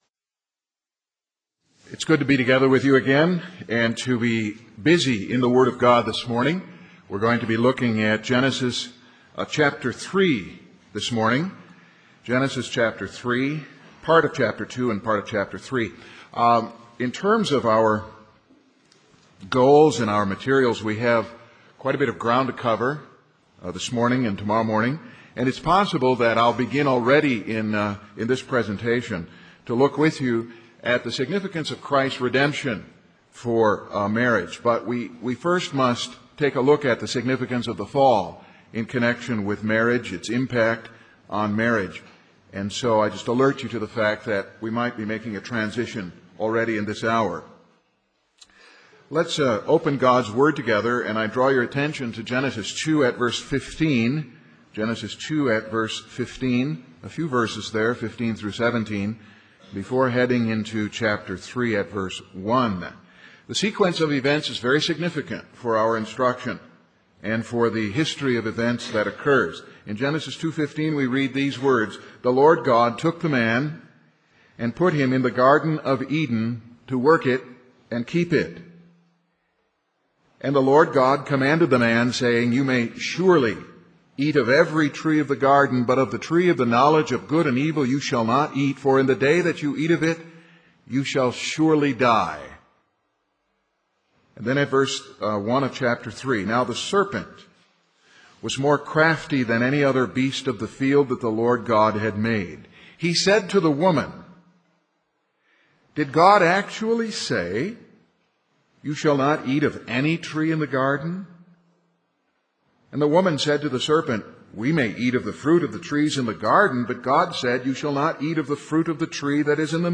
Sermons | Grace Minister's Conference